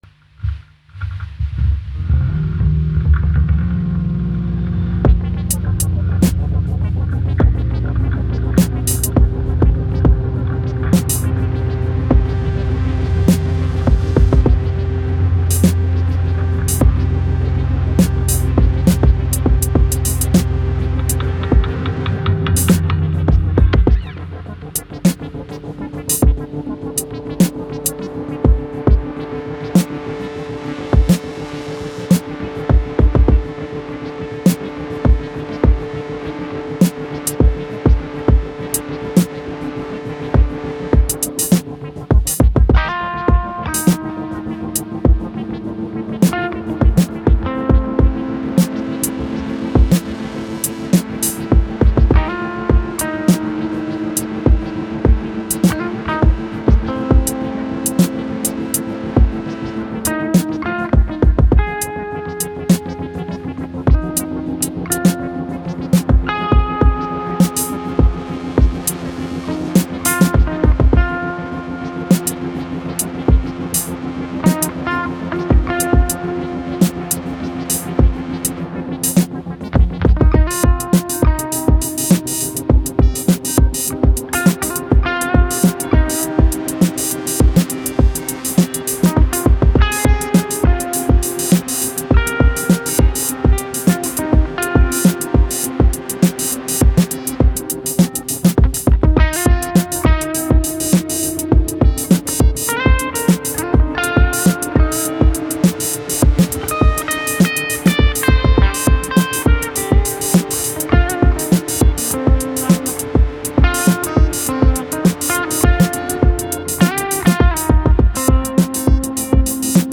I wanted to test out how far I can push my old Bassman-Type Amp Pedal. The cleanish Tones are created by just rolling down the Volume Knob. FX, Beats & Drone courtesy of the A4.